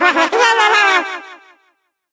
evil_gene_vo_03.ogg